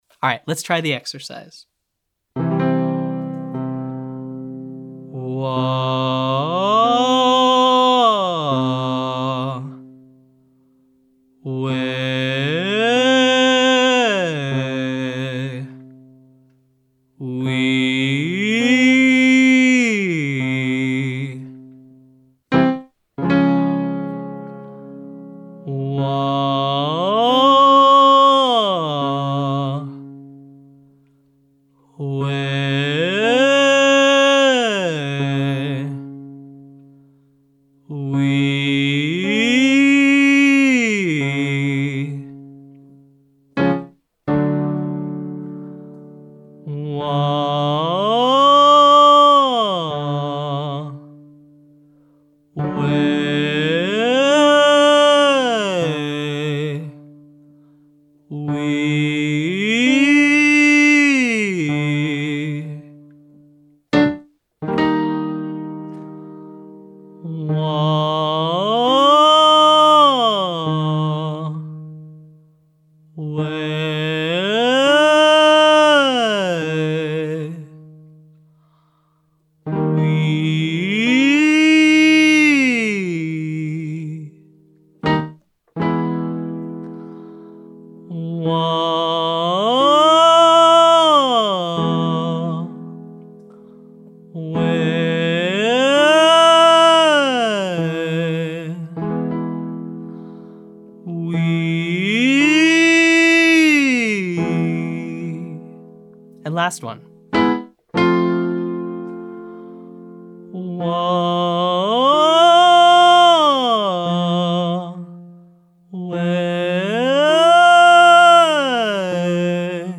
Exercise 1: Wah, Weh, Wee 1-8-1 chest up
I’m using 3 different vowels, starting with a Wah, then a Weh, and lastly a Wee.